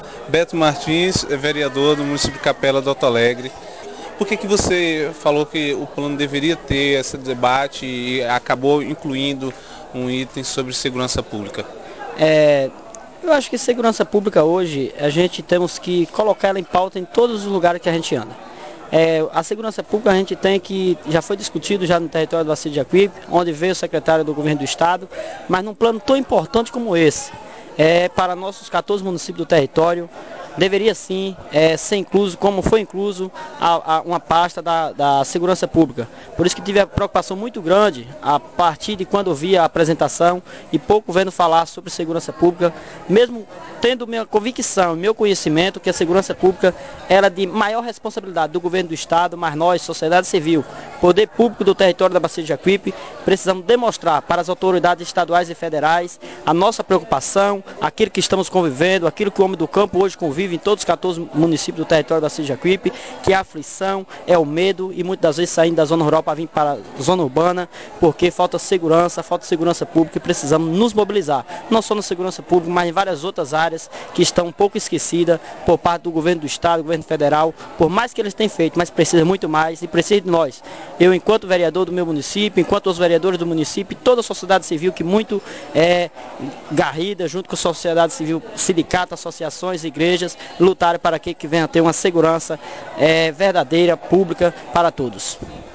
A reivindicação foi do vereador, Valdoberto Martins, do município de Capela do Alto Alegre.